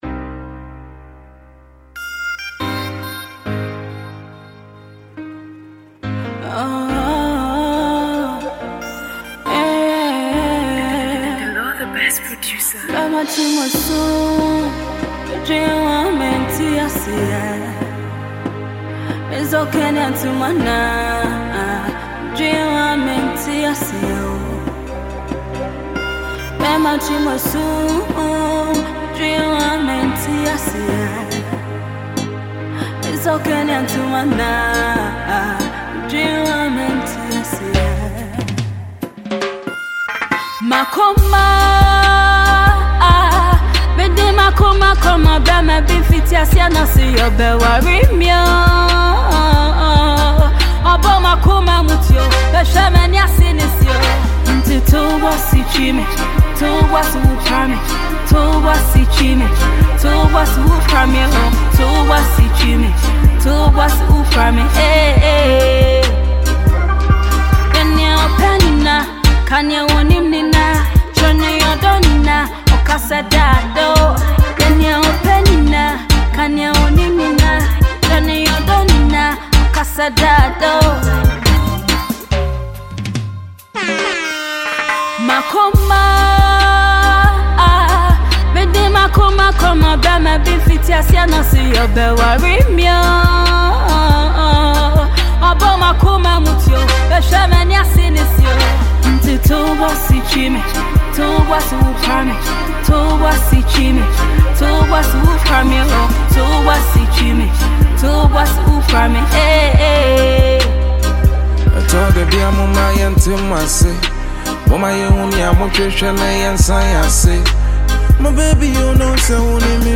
Ghana Music
Ghanaian songstress